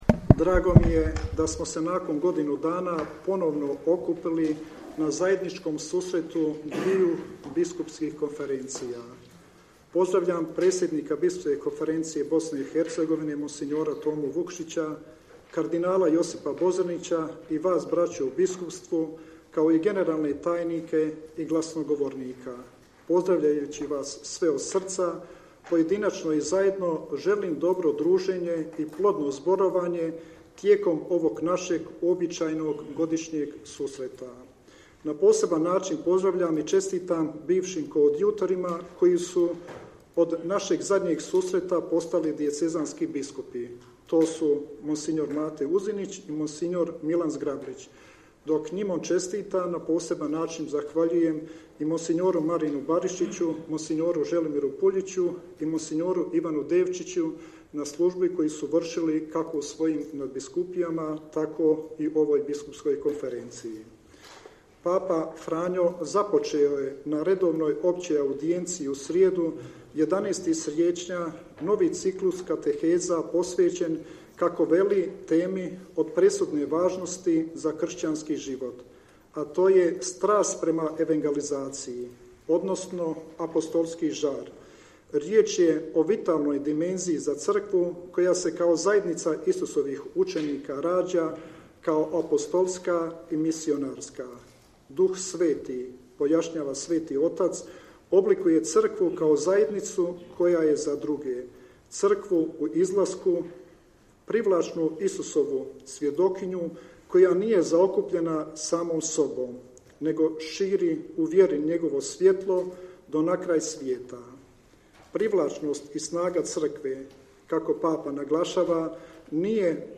Predsjednik Hrvatske biskupske konferencije splitsko-makarski nadbiskup i porečki i pulski apostolski upravitelj mons. Dražen Kutleša je na početku 25. zasjedanja Hrvatske biskupske konferencije i Biskupske konferencije Bosne i Hercegovine u sjedištu HBK u Zagrebu izrekao pozdravni govor koji prenosimo u cijelosti.